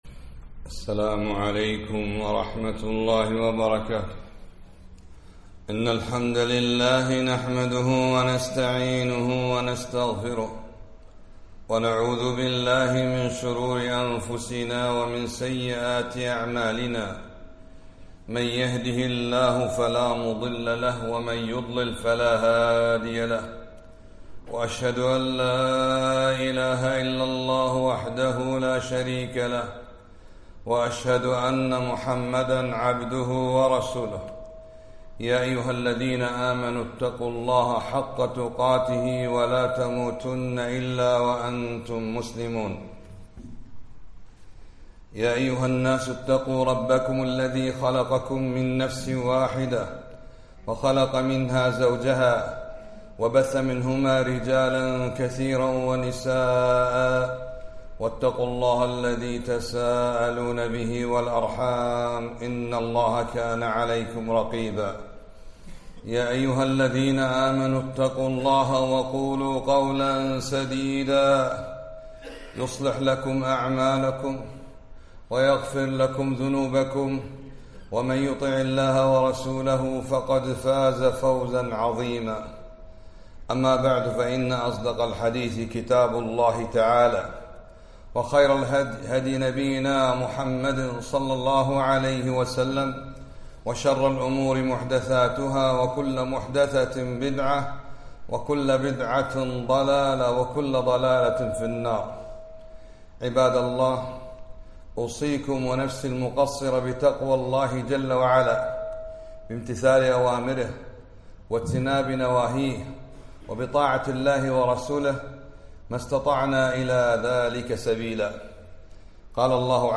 خطبة - ما يجب على المسلم فعله عند الابتلاء